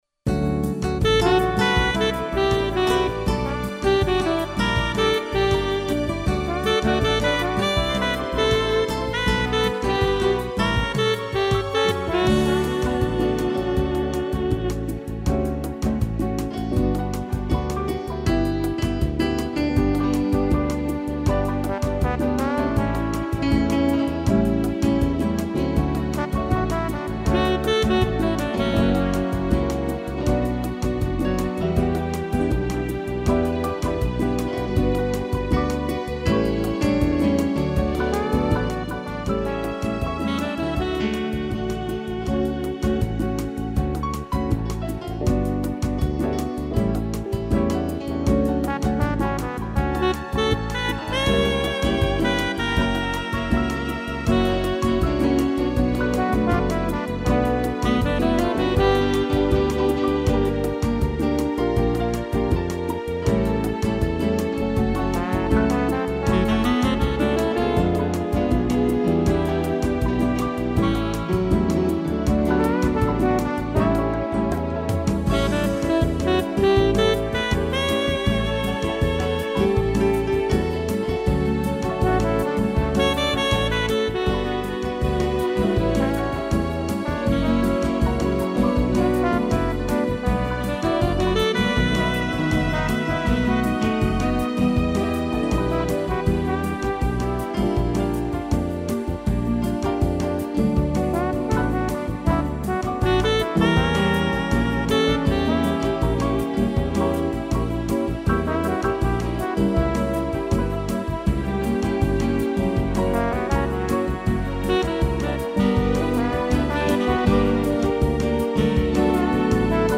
piano, sax, trombone e strings
(instrumental)